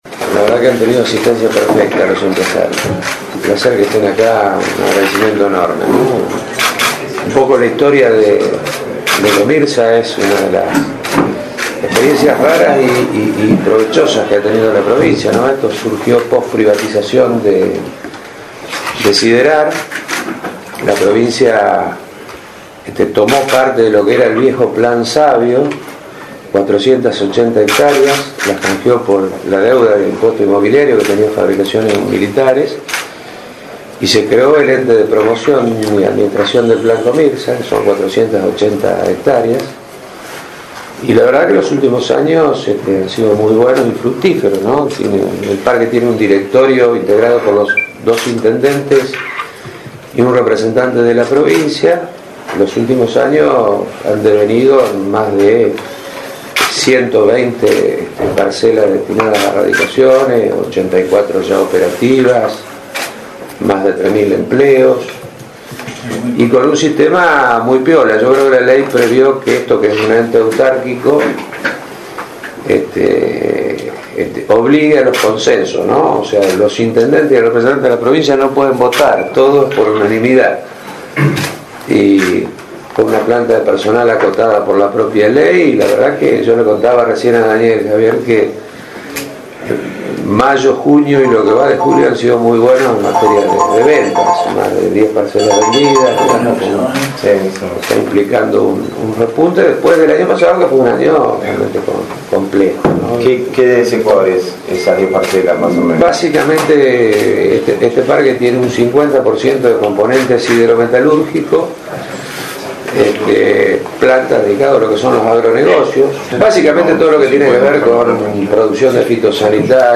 Los días miércoles 5 de julio y jueves 6 de julio se realizó en el Teatro Municipal de San Nicolás los actos donde se entregaron las Becas al Mérito que otorga la Fundación Hermanos Agustín y Enrique Rocca.